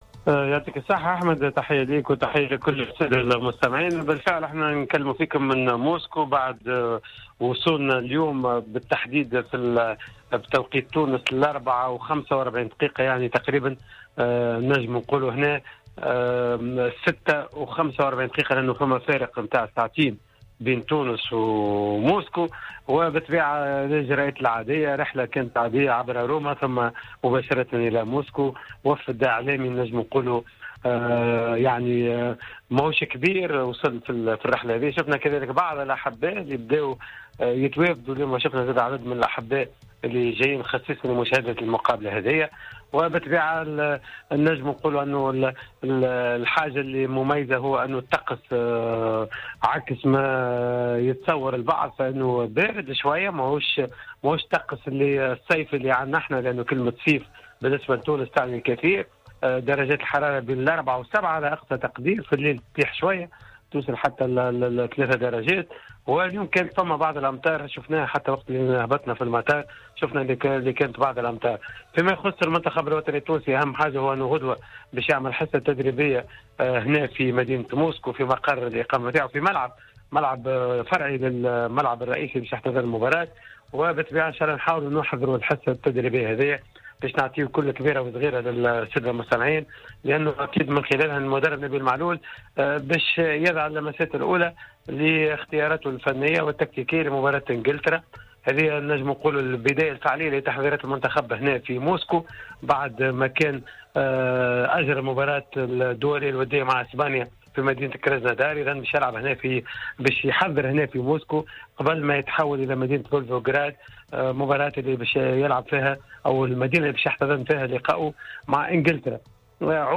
مباشرة من موسكو